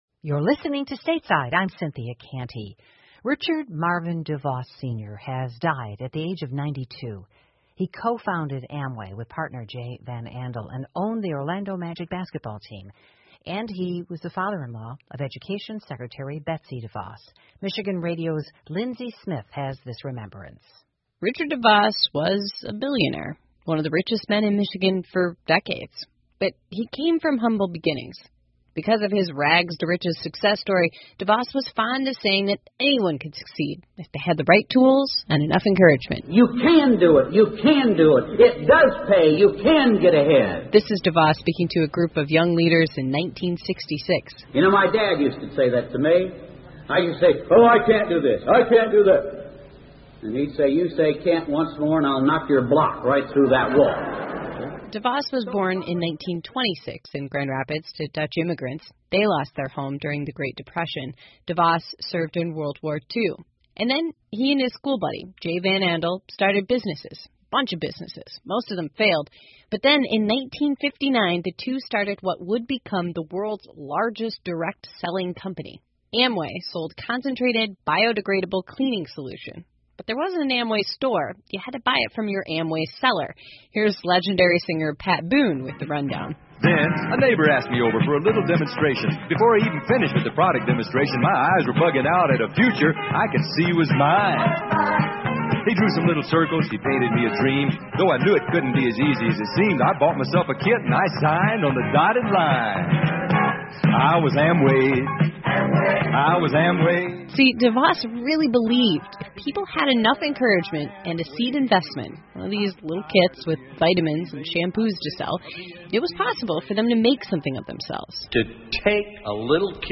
密歇根新闻广播 安利公司创始人之一德沃斯去世,享年92岁 听力文件下载—在线英语听力室